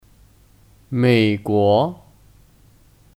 美国 (Měiguó 美国)